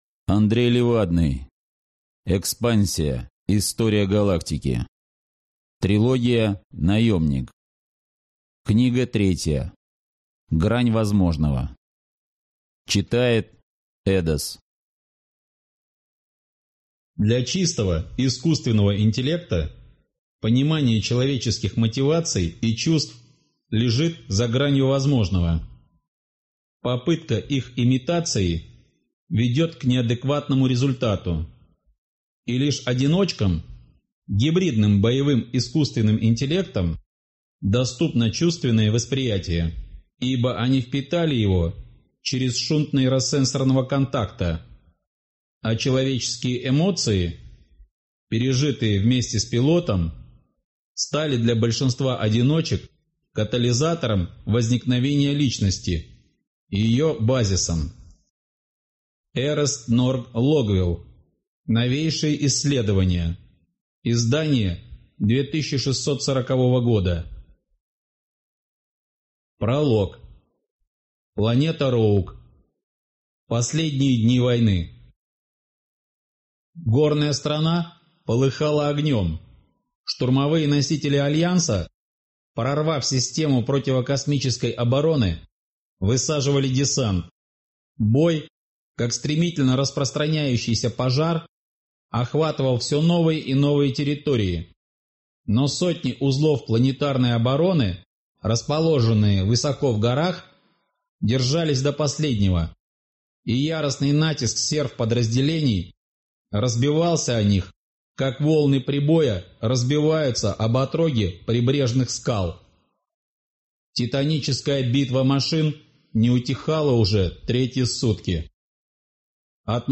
Аудиокнига Наемник. Книга 3. Грань возможного | Библиотека аудиокниг